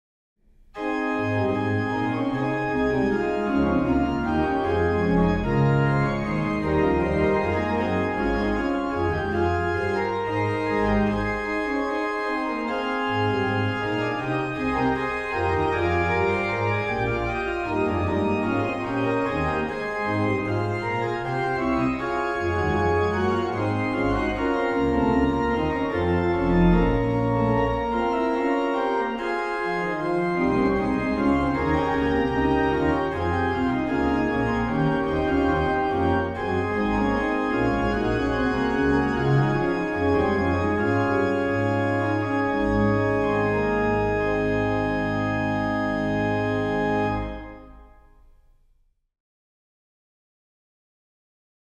Registration   MAN: Pr8, Viol8, Oct4, Oct2
PED: Sub16, Viol16, Oct8, Oct4